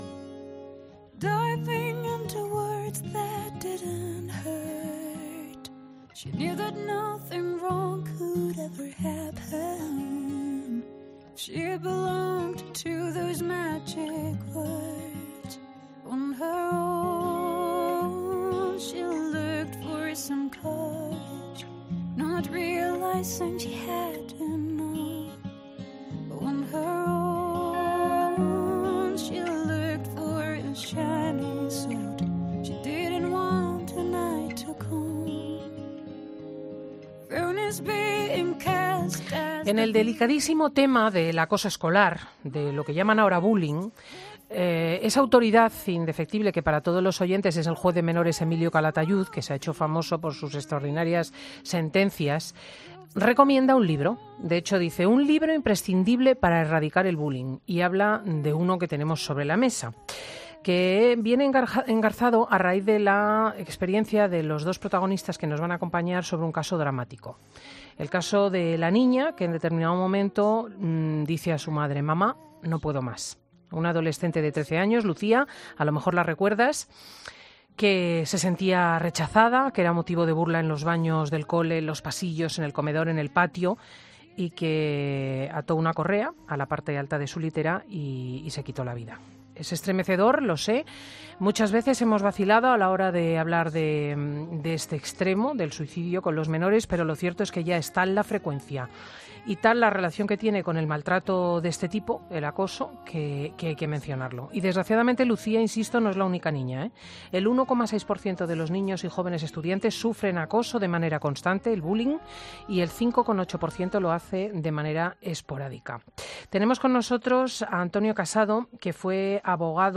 No te pierdas toda la entrevista en Fin de Semana.